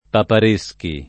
[ papar %S ki ]